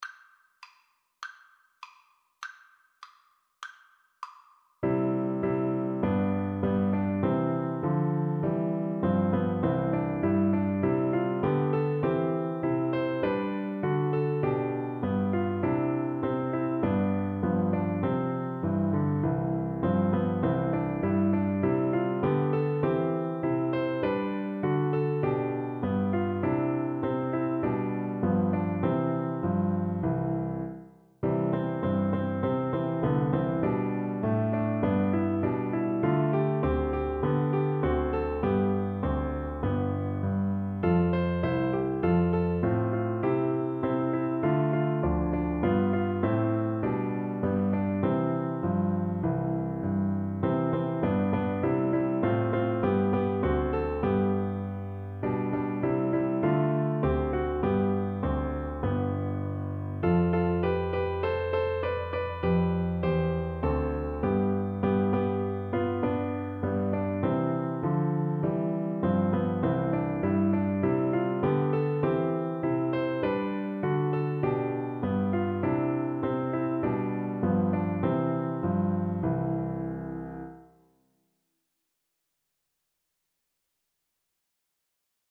Voice
2/4 (View more 2/4 Music)
C major (Sounding Pitch) (View more C major Music for Voice )
Moderato
Traditional (View more Traditional Voice Music)
National Anthems